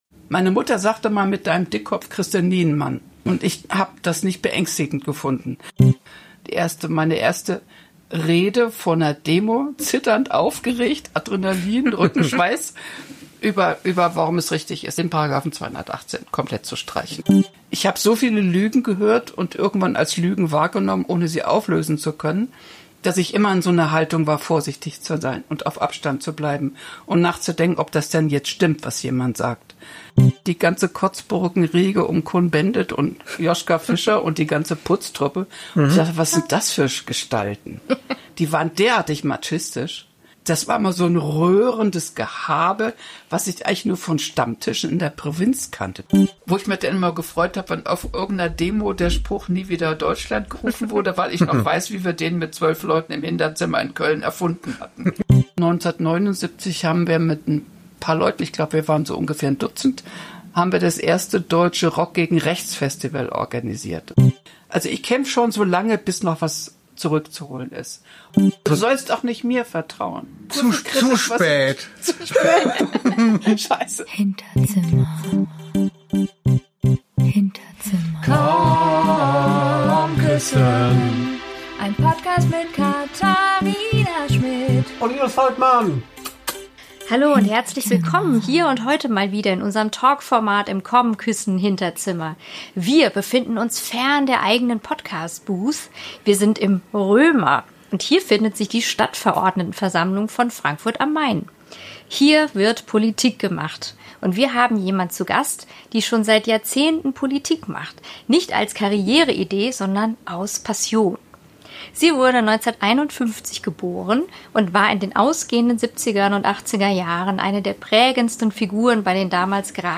Ein Gespräch...